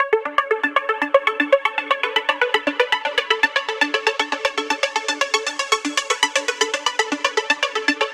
Essentials Key Lead 5 118 bpm.wav
Original creative-commons licensed sounds for DJ's and music producers, recorded with high quality studio microphones.
sc_essentials_key_lead_5_118_bpm_tdp_bd5.ogg